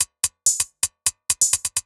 Index of /musicradar/ultimate-hihat-samples/128bpm
UHH_ElectroHatD_128-02.wav